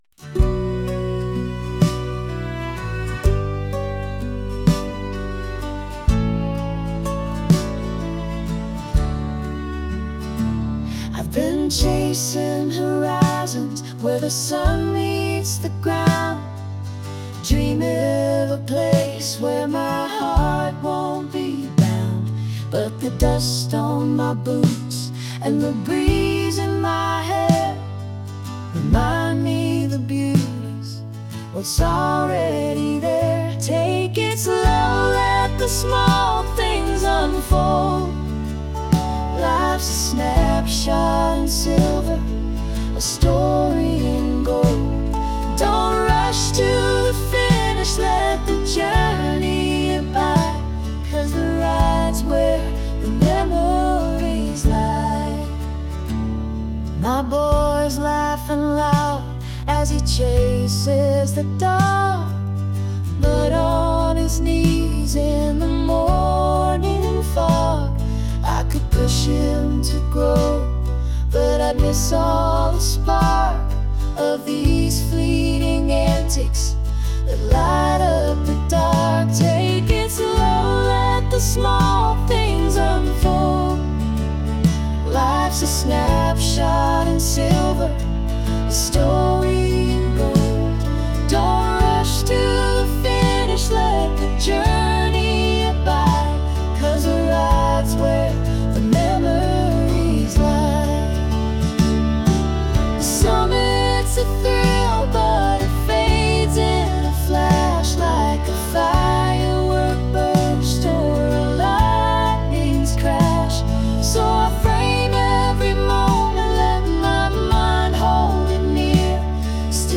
Country, Folk